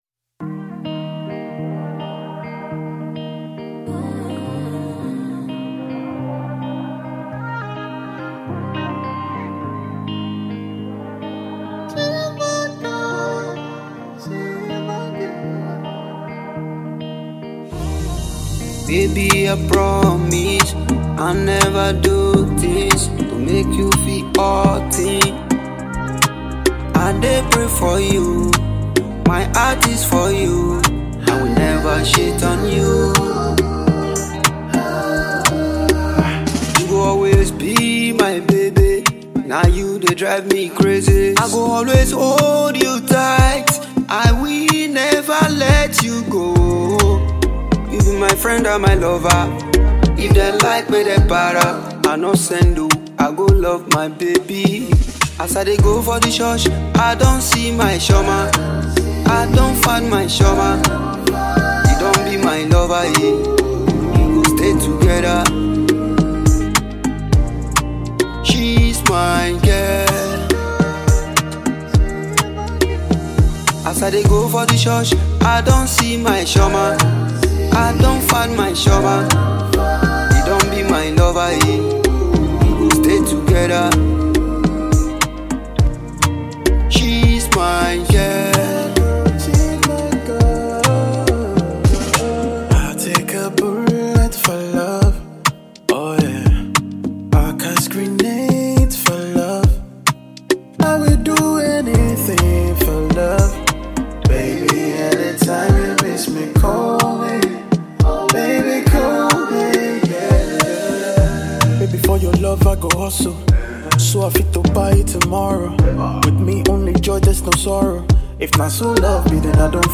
Delta base Afro singer